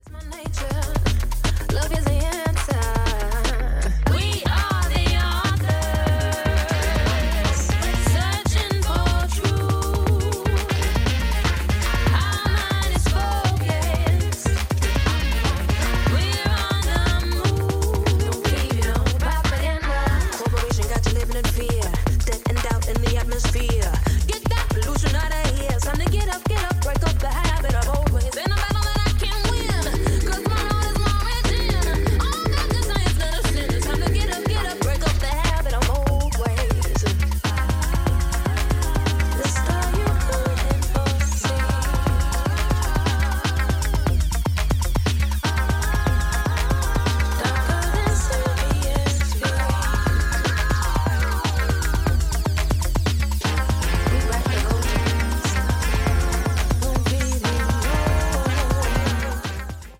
remix 45